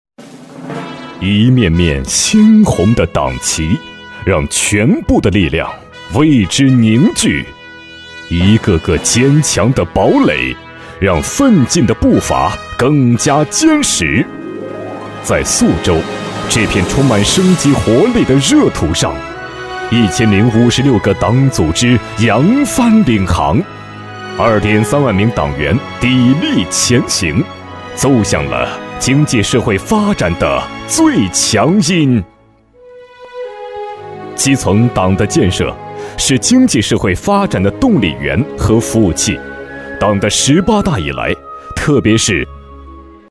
大气震撼 企业专题,人物专题,医疗专题,学校专题,产品解说,警示教育,规划总结配音
质感男音，擅长大气激情，稳重厚实专题、宣传片等。